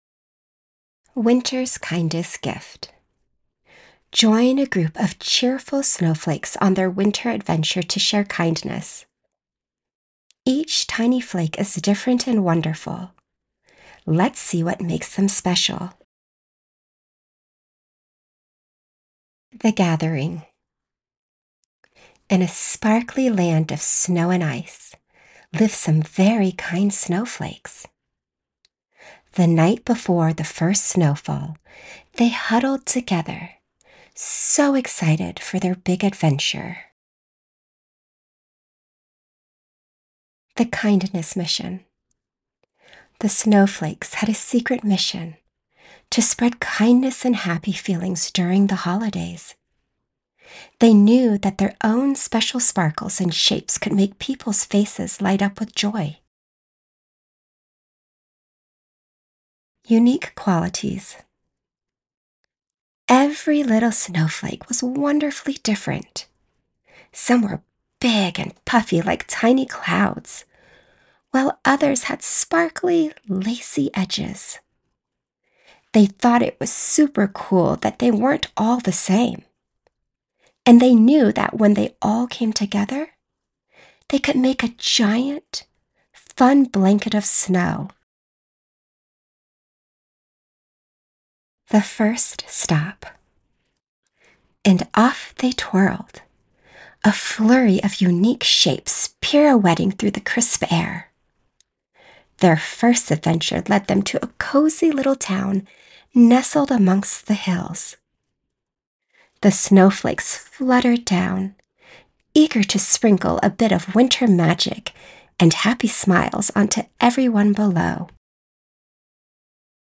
Narration - Children's Story Sample
Middle Aged